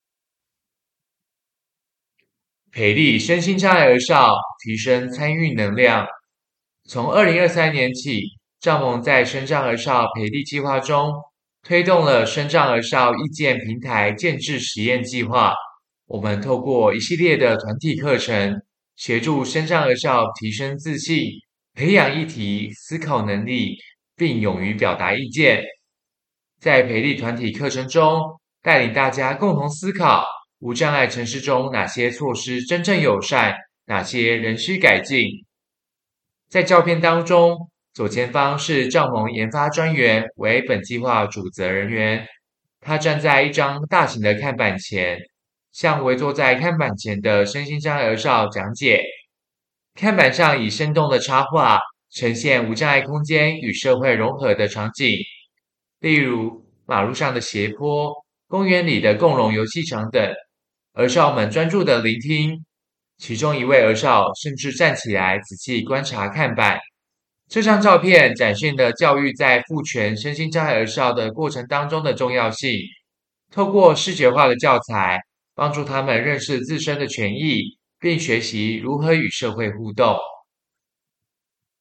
所有文字內容會轉成語音檔，每幅作品旁都附有語音 QR-Code，讓視障朋友或不便閱讀的民眾能掃描聆聽，用「聽」的方式來欣賞作品。